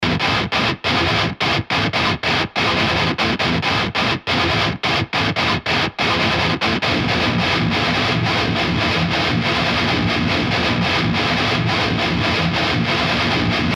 гитары - железная грелка Chris Custom Screamer, далее софтовые poulin legion и sir 2 для импульсовбас - markbass + жужжалки и с...